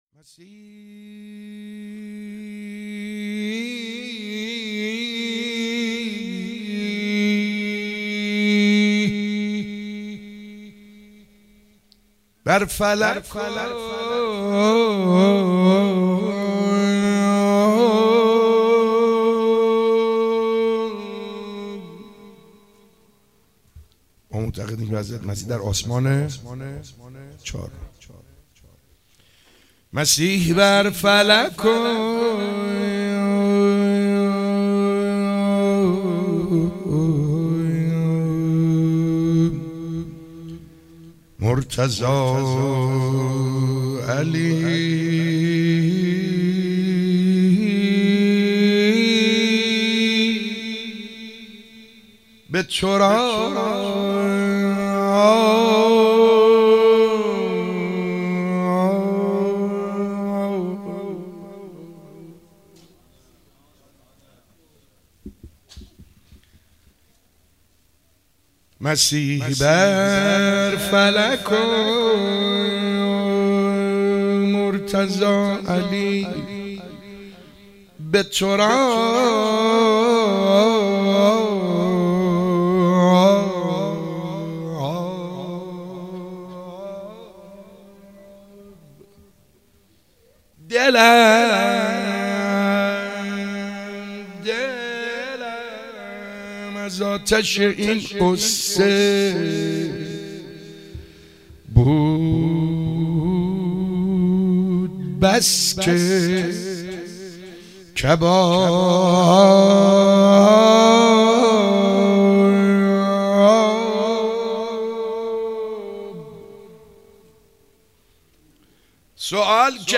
ولادت سعید حدادیان مدح مولودی